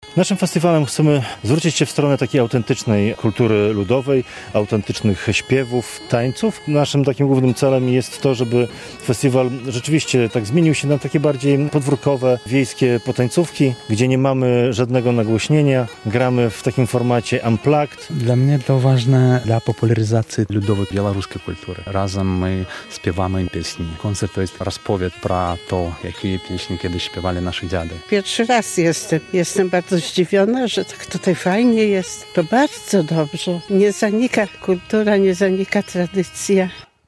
W Słuczance niewielkiej miejscowości w gminie Gródek rozpoczął się 4. Festiwal Białoruskiej Tradycji "Soncahraj".